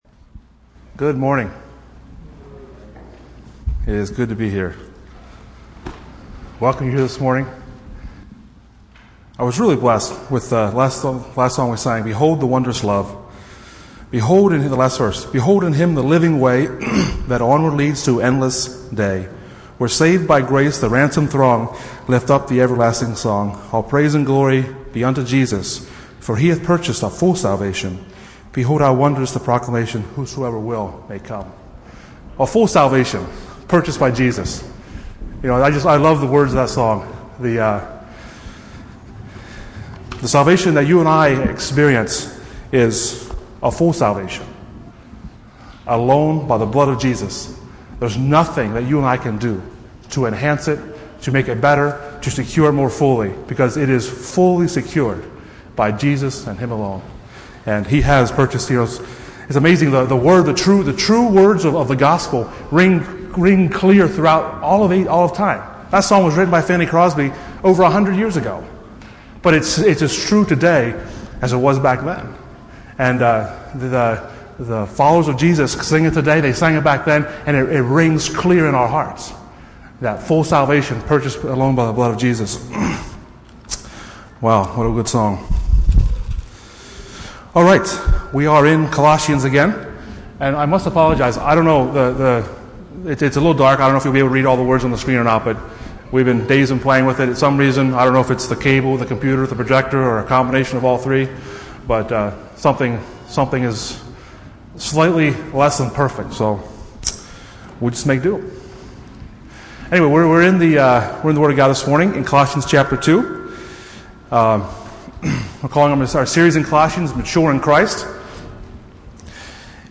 Sunday Morning Studies in Colossians Passage: Colossians 2:6-15 Service Type: Sunday Morning %todo_render% « Good and Godly Music The Resurrection